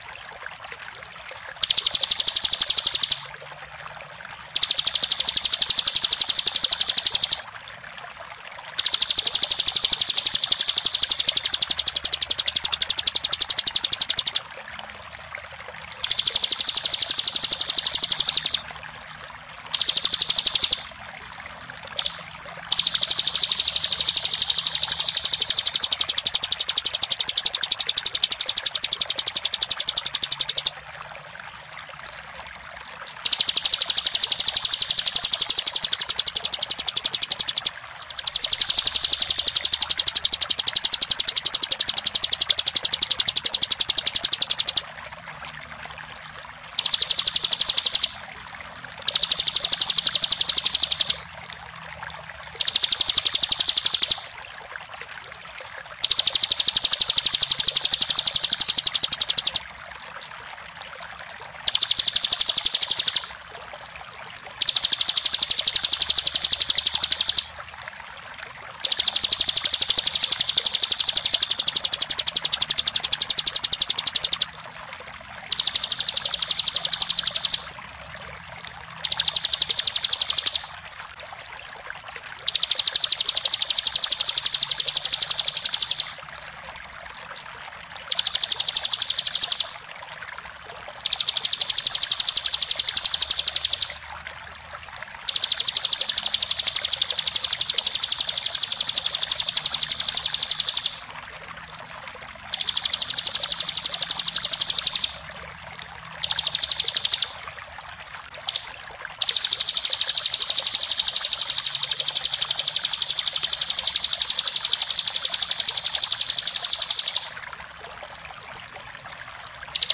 Suara Masteran Burung Tembakan AK 47
Kategori: Suara burung
🎶✨ Suara masteran burung tembakan AK 47 ini cocok banget untuk melatih burung kesayanganmu agar gacor dan lebih aktif. Dengan suara tembakan khas yang tajam dan jelas, burung kamu pasti makin rajin berkicau!
suara-masteran-burung-tembakan-ak-47-id-www_tiengdong_com.mp3